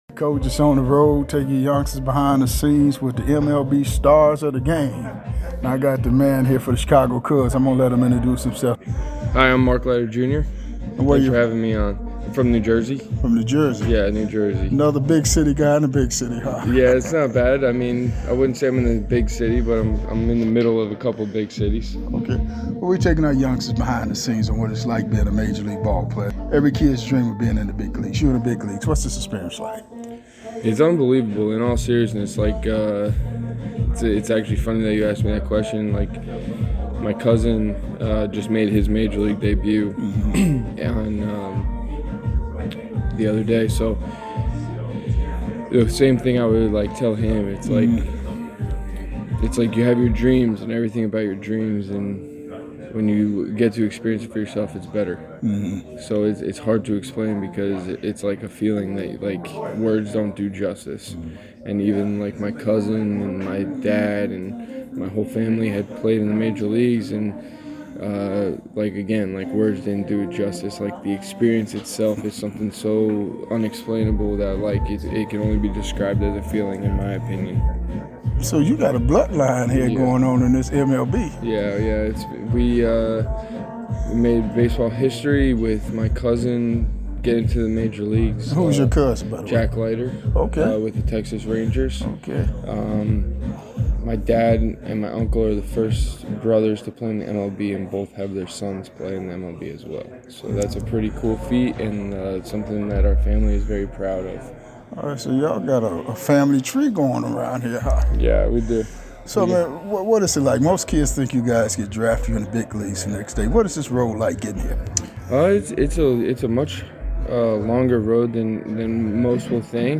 MLB Classic Interviews